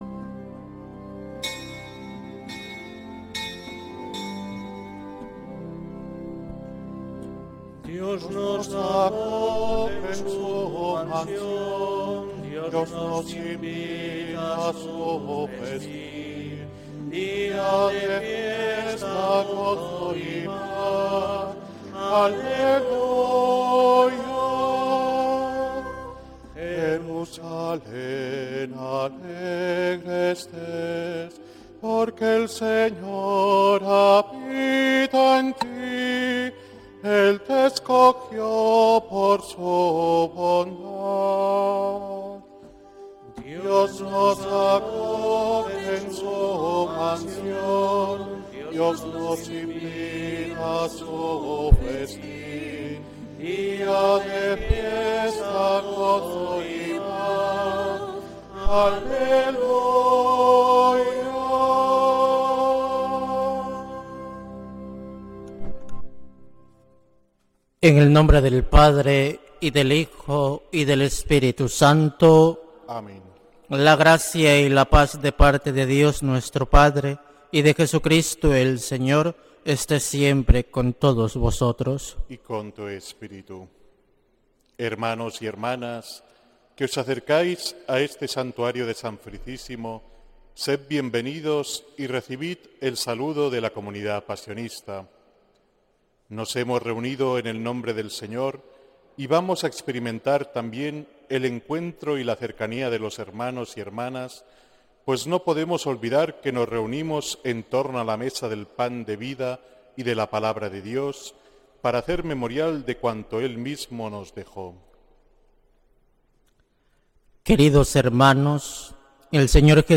Santa Misa desde San Felicísimo en Deusto, domingo 17 de agosto de 2025